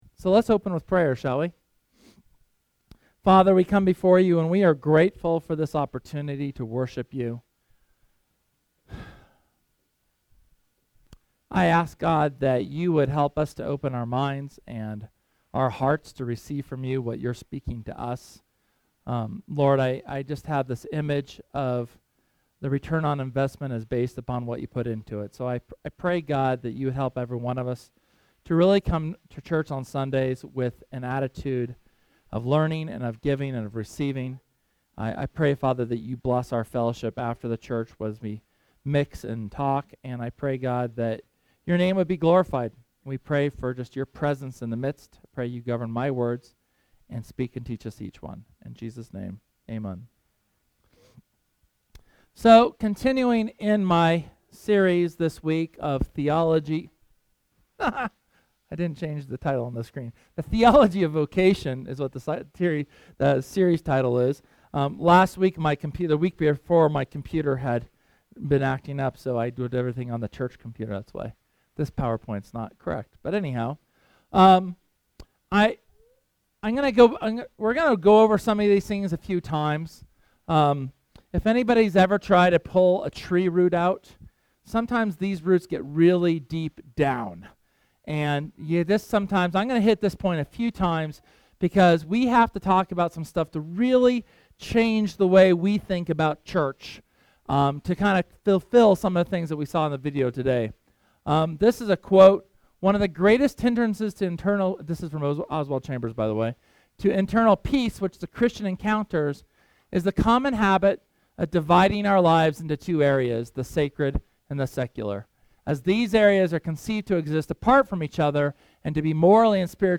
The second sermon in our series on the theology of our vocation.